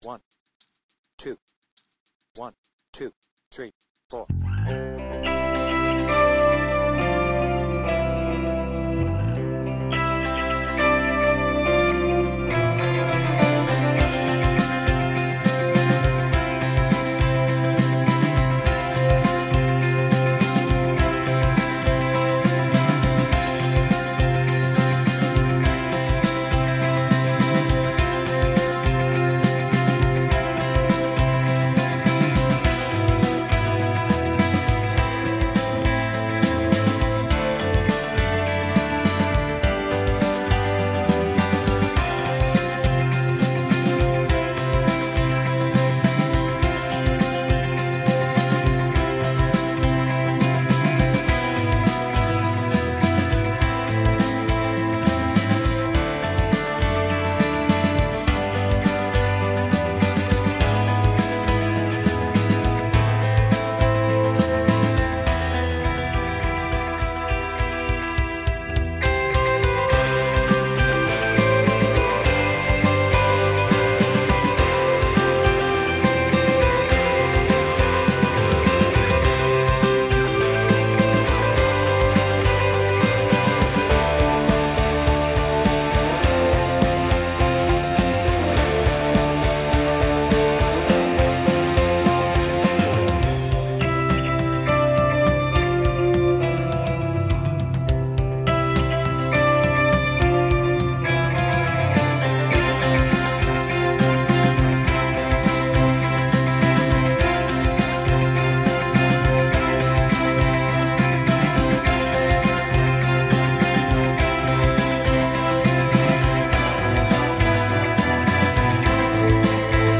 BPM : 103
Tuning : E
Without vocals